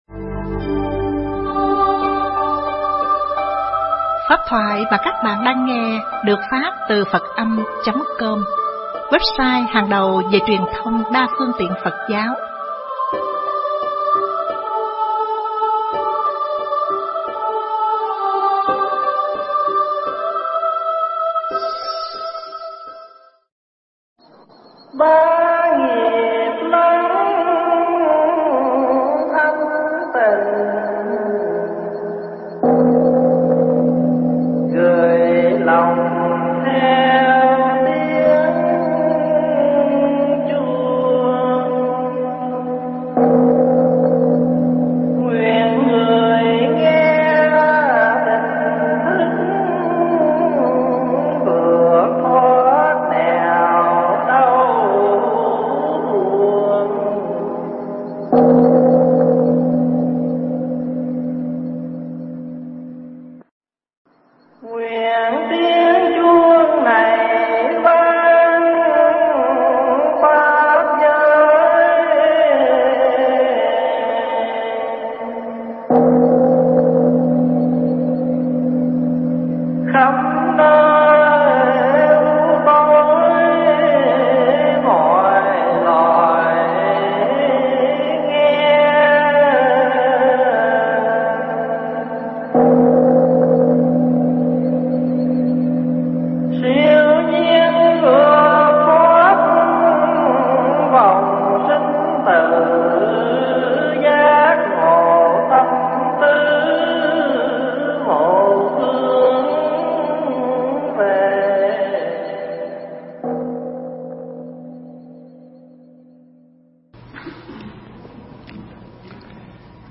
Nghe Mp3 thuyết pháp Bồ Tát Tại Gia Phần 71